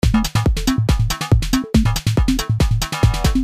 Sonido Loop 12 de 12
treetecno.mp3